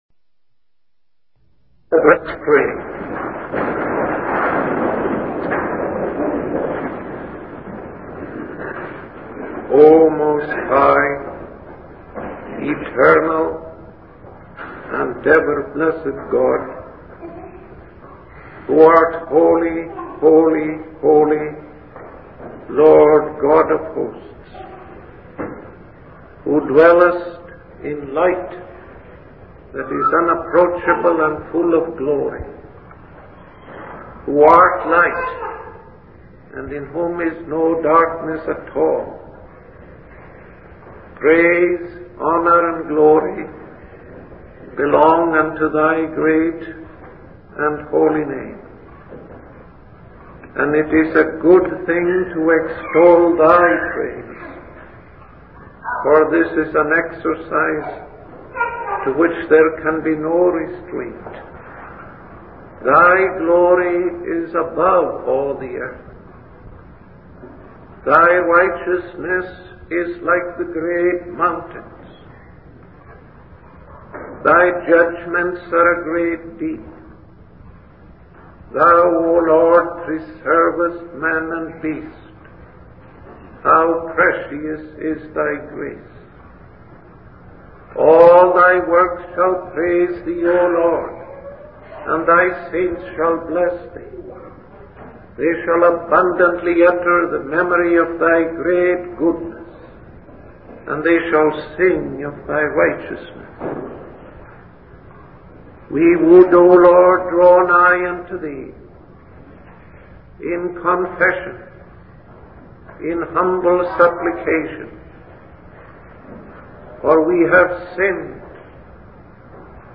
In this sermon, the preacher emphasizes the temporary nature of worldly desires and urges the listeners not to pattern their lives after passing fashions.